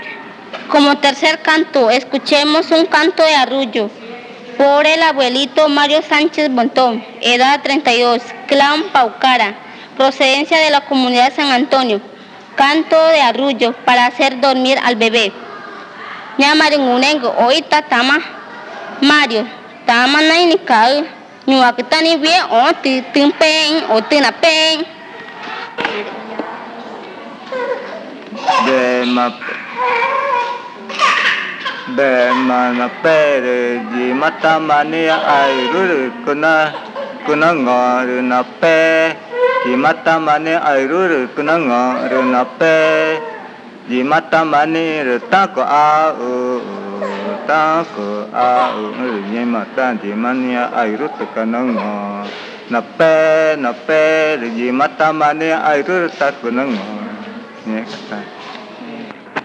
Lullaby 6
Comunidad Indígena Nazareth
Arrullo cantado por el abuelo
during the III Meeting of Elders and Parents organized in the Indigenous Community of Nazareth (Amazonas, Colombia)